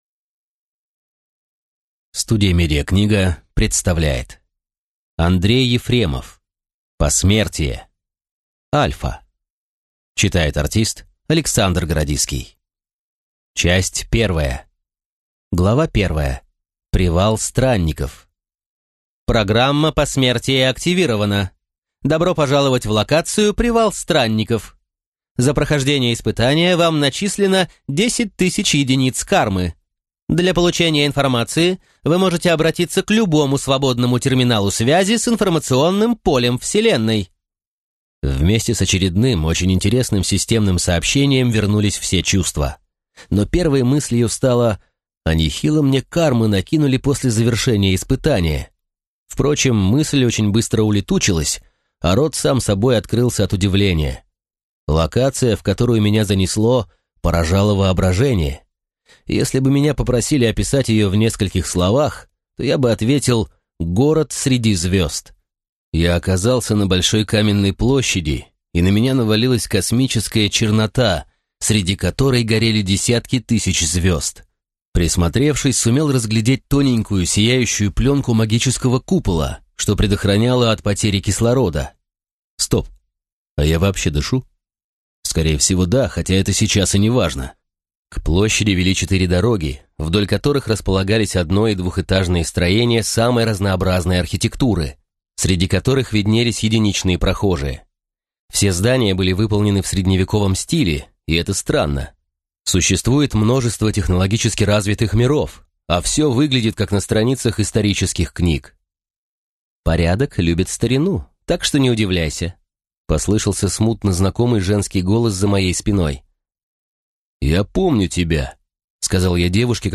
Аудиокнига Посмертие-5. Альфа. Часть первая | Библиотека аудиокниг